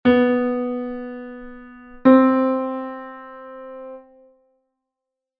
si_do_semitono.mp3